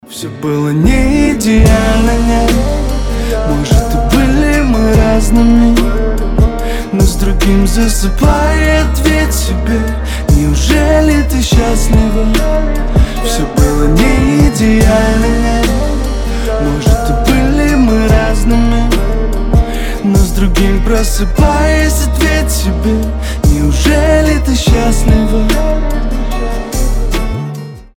лирика
грустные
медленные